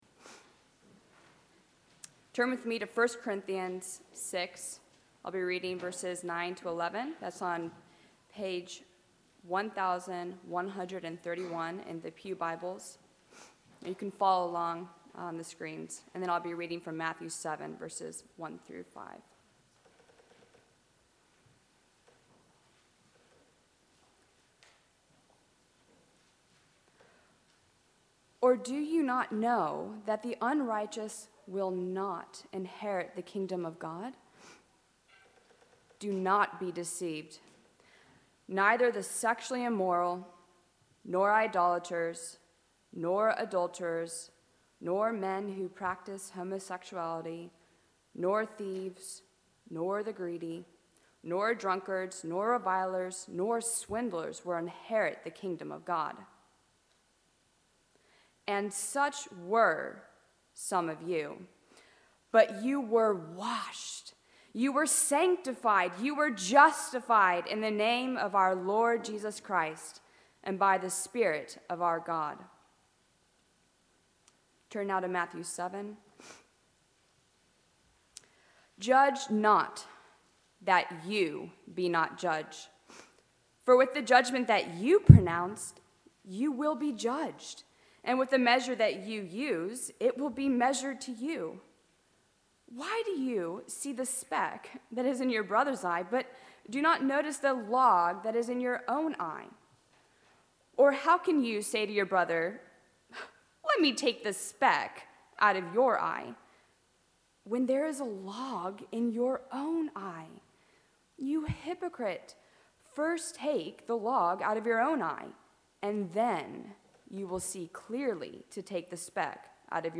A message from the series "Learning Christ."